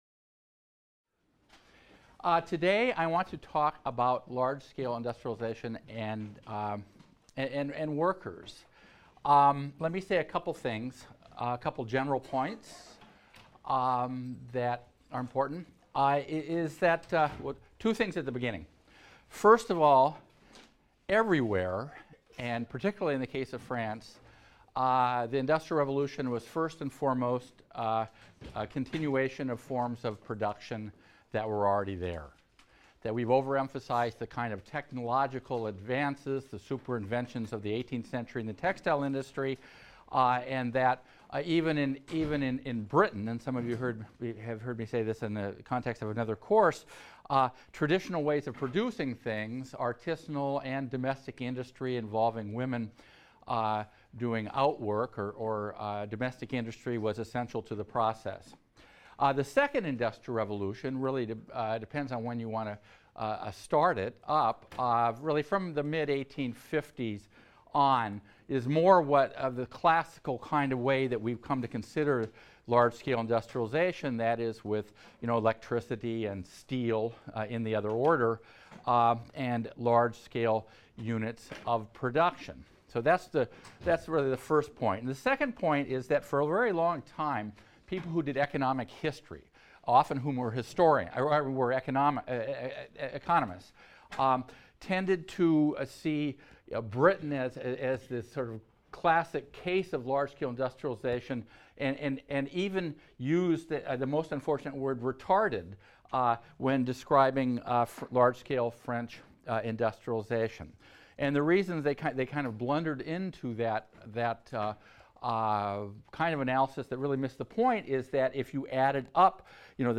HIST 276 - Lecture 6 - Workshop and Factory | Open Yale Courses